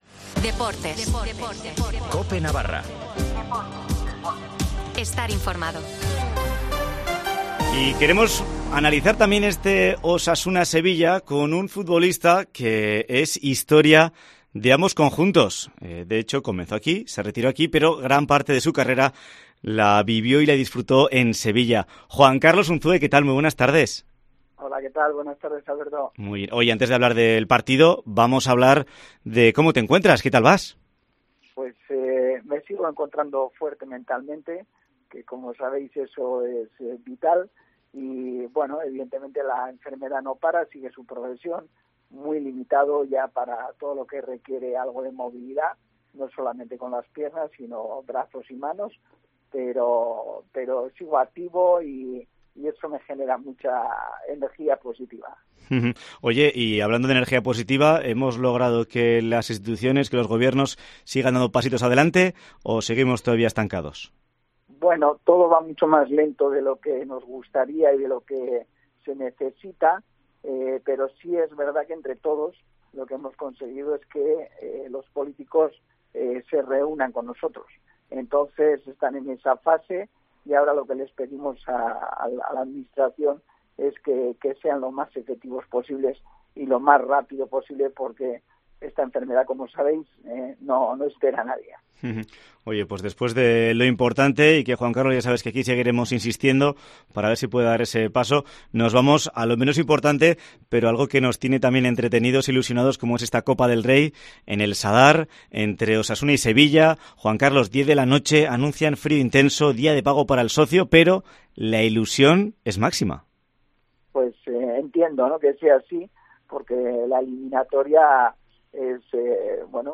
Entrevista con Juan Carlos Unzué antes del Osasuna-Sevilla de Copa del Rey